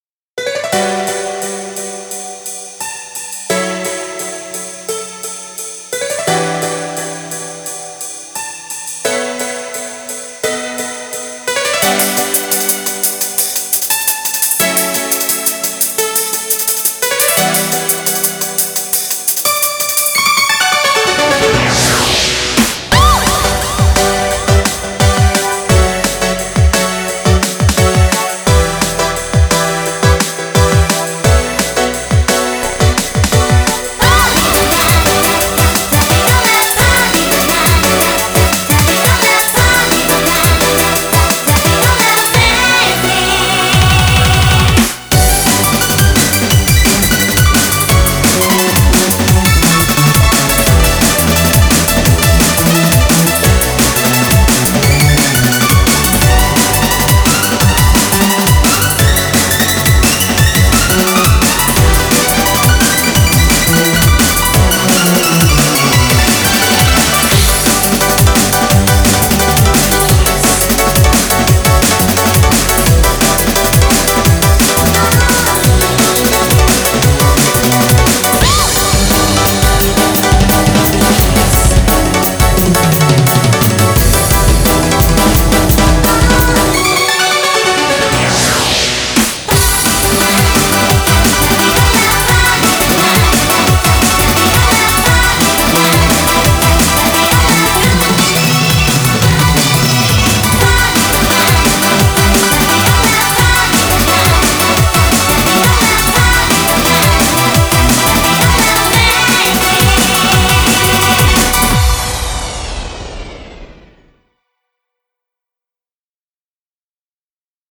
BPM173
Audio QualityPerfect (High Quality)
Genre: DRUM & BASS.